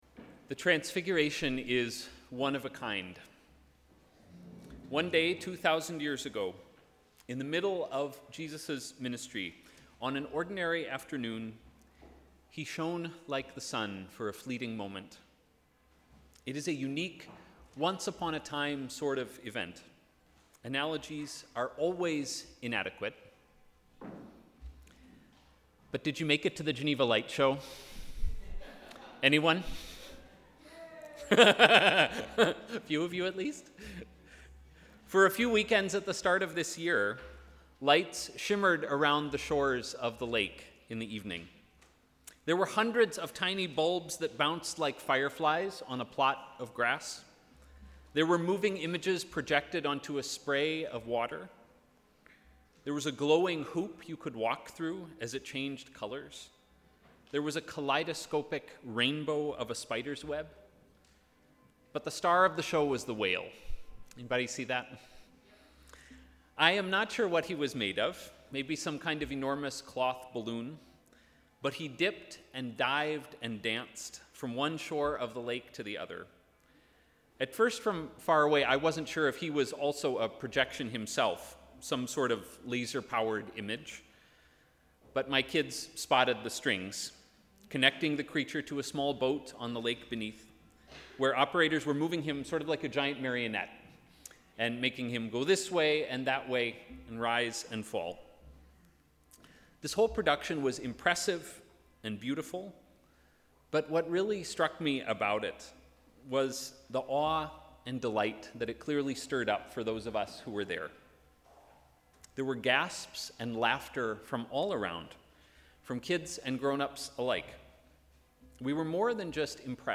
Sermon: ‘Awe and delight’